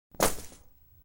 Звук букета с цветами положили на стол